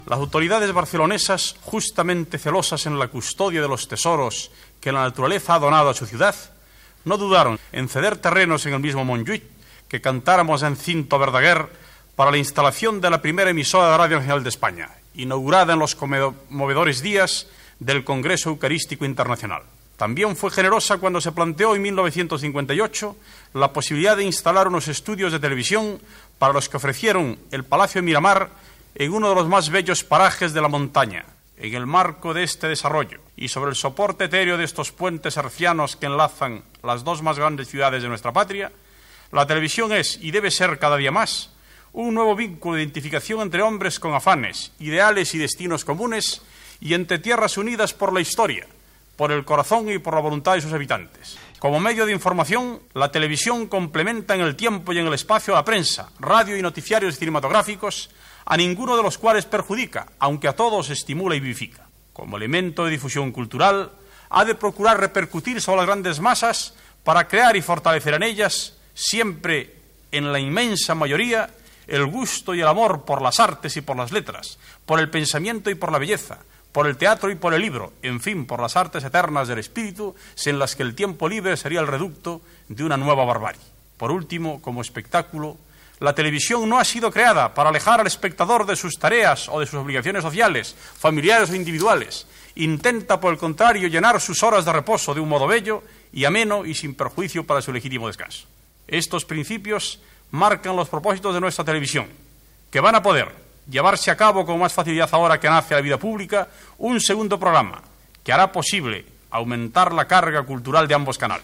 Discurs del ministre d'Información i Turisme espanyol Manuel Fraga Iribarne el dia de la inauguració de les emissions del segon canal de Televisió Espanyola en UHF a Barcelona, fetes des de la muntanya del Tibidabo
Informatiu